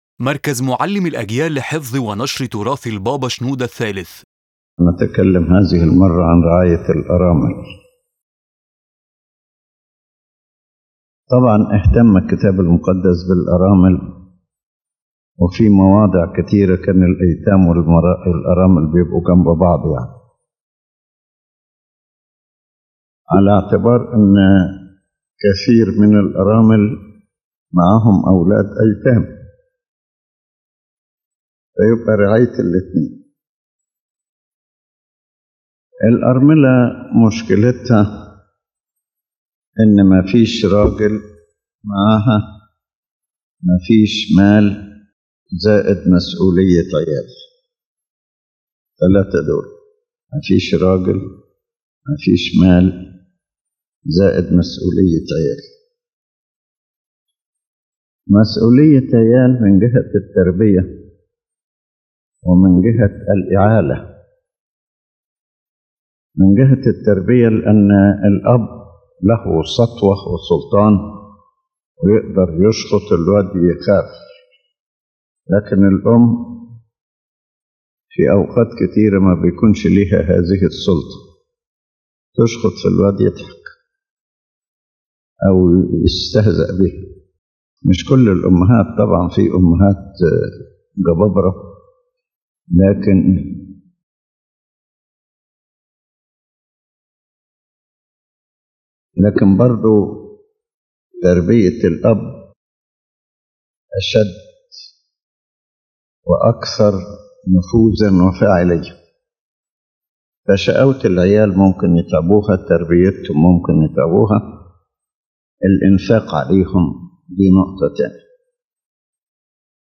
His Holiness Pope Shenouda III explains in depth the human, spiritual, and social role the Church must carry out toward widows, considering them among the most needy for care. The lecture shows a high pastoral sensitivity that combines consolation, assistance, care for children, finding sources of income, and protection from psychological and social dangers.